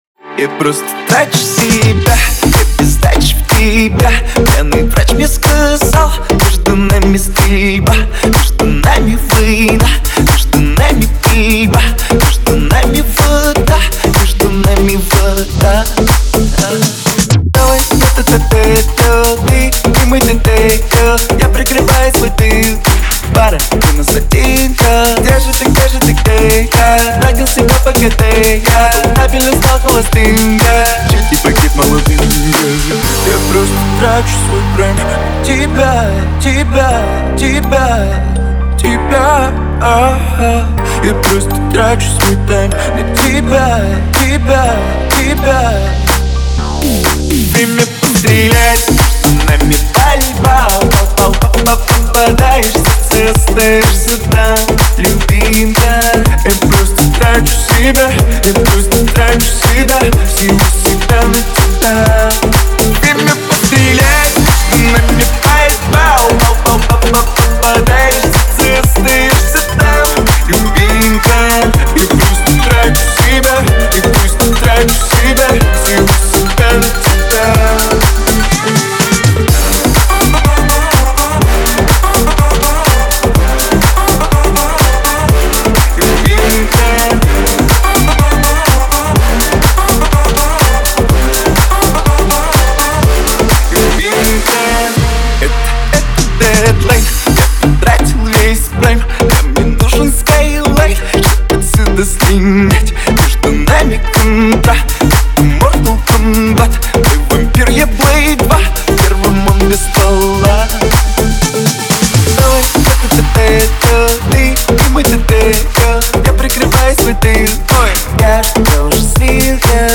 Стиль: Dance / Pop